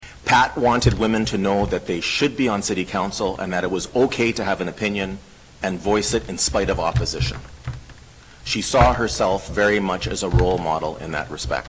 Members of Culhane’s family sat in the chambers as Mayor Mitch Panciuk led off the tribute.